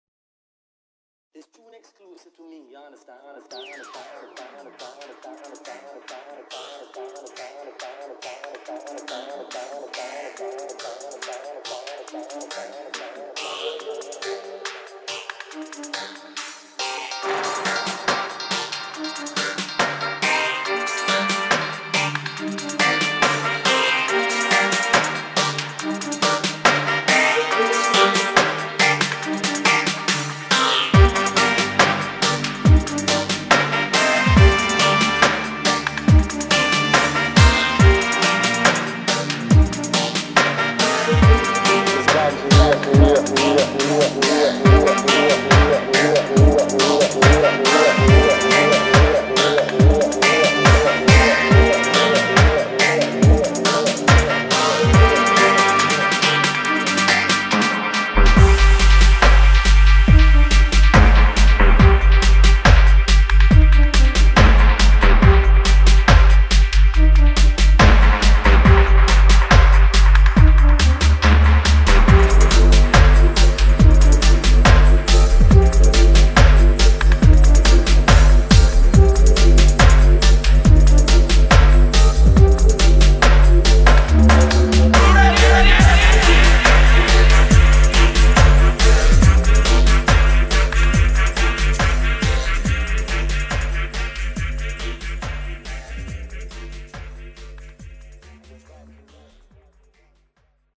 [ DUBSTEP / DUB ]